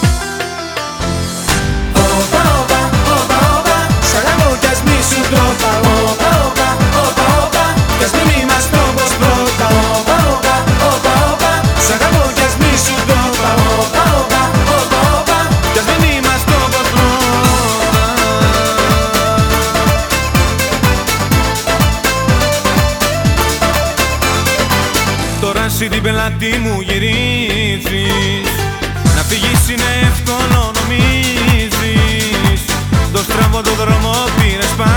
1999-07-05 Жанр: Поп музыка Длительность